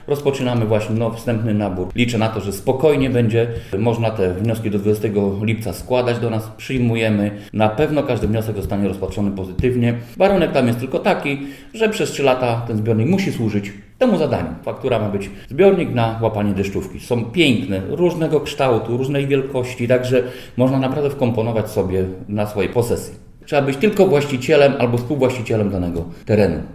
Jak mówi Leszek Mrożek, wójt żarskiej gminy warto pomyśleć o projekcie nie tylko pod względem finansowym, ale i ze względu na ekologię i oszczędność wody: